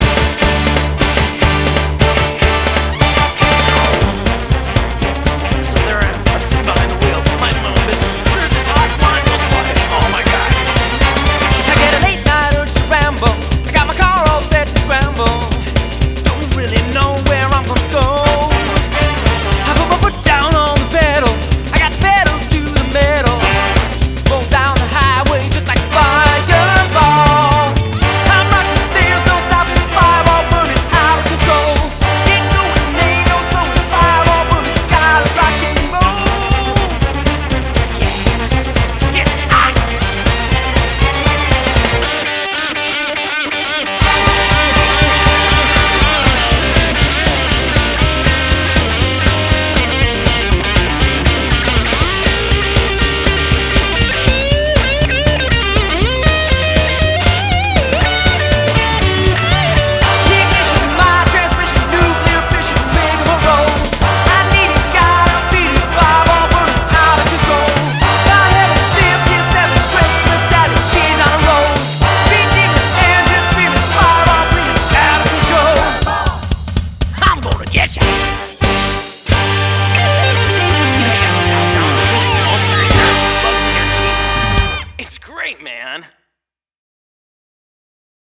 BPM240
rockabilly classic